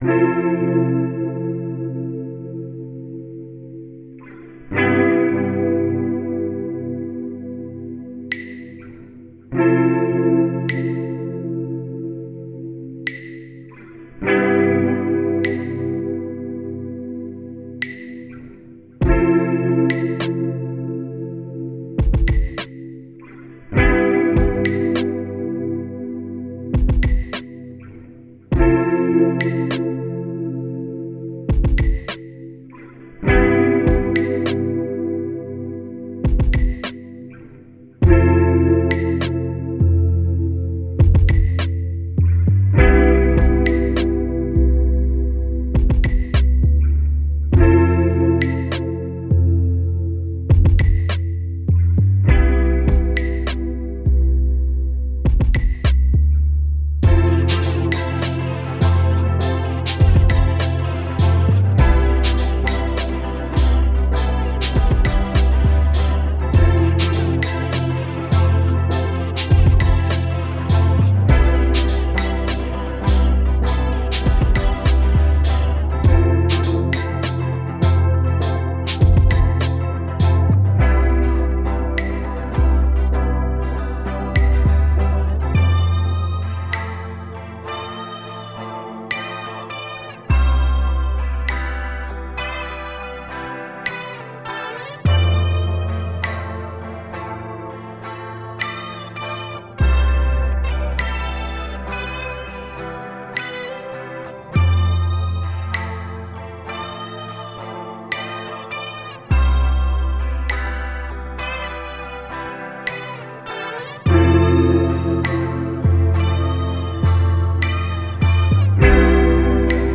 For this song, I was messing around with my guitar and played a Fmaj7 barre chord and then a Cmaj7 barre chord. I thought that they sounded very nice together I then recorded the chords into Ableton and added some reverb, delay, channel EQ, and amp effects to it. After this, I took some drums from my sample packs and made a drum rack that I felt matched the guitar and recorded a drum beat. Next, I added some simple bass to give the song a little more feeling to it. Finally, I added two riffs that really add the touches and emotions to the song that I wanted.